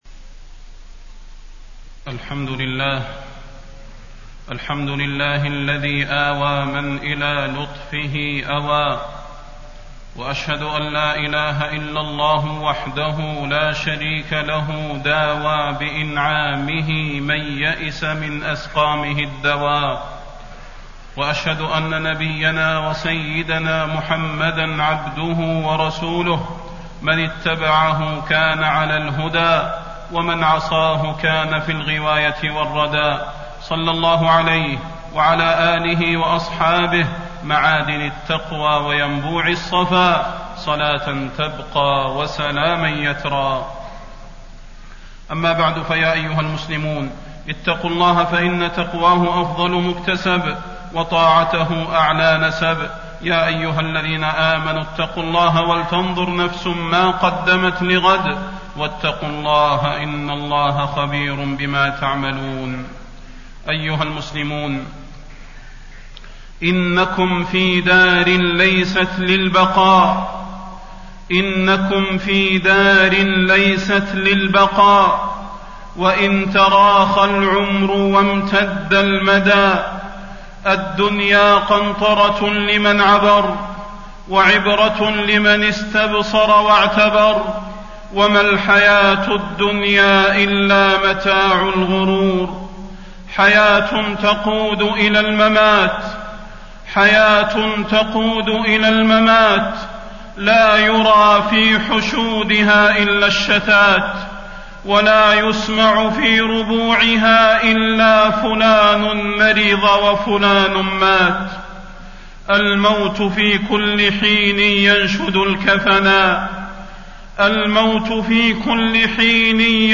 فضيلة الشيخ د. صلاح بن محمد البدير
تاريخ النشر ١٤ جمادى الأولى ١٤٣٣ هـ المكان: المسجد النبوي الشيخ: فضيلة الشيخ د. صلاح بن محمد البدير فضيلة الشيخ د. صلاح بن محمد البدير التوبة قبل فوات الأوان